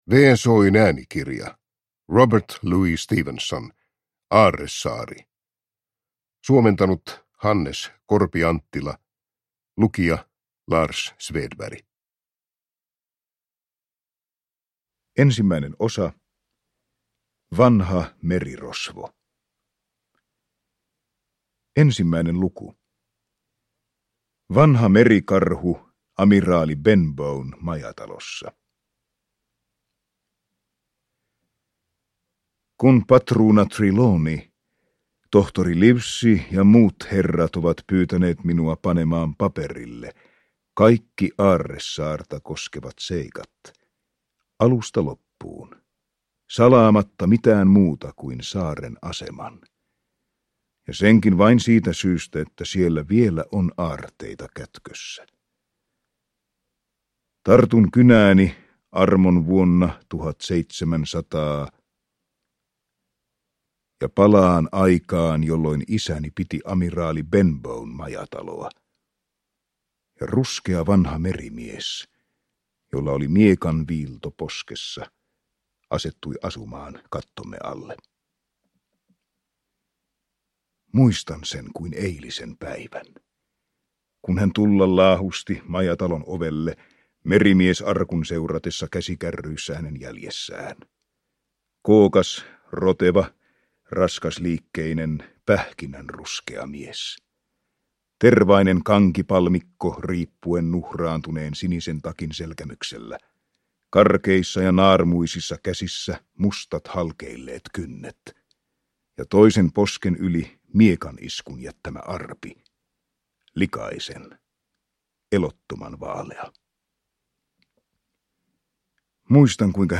Aarresaari – Ljudbok – Laddas ner